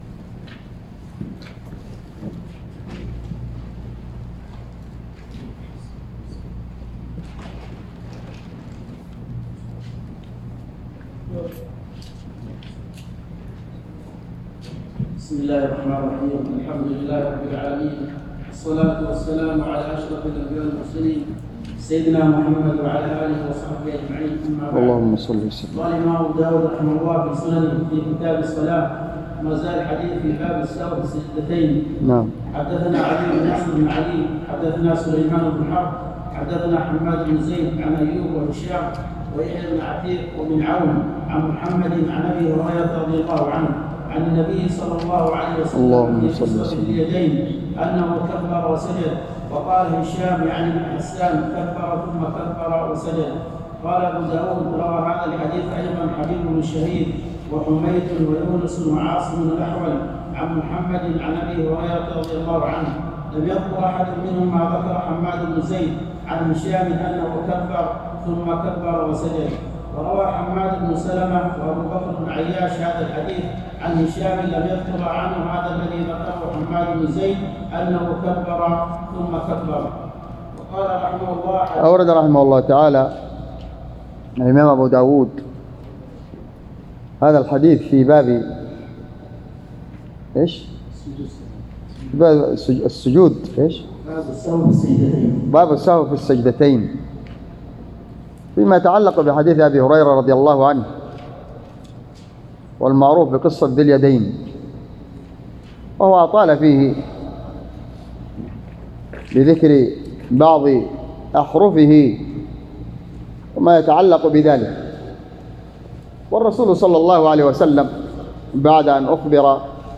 شرح كتاب الصلاة - سنن أبي داود | ٢٨ جمادى الآخر ١٤٤٤ هـ _ بجامع الدرسي صبيا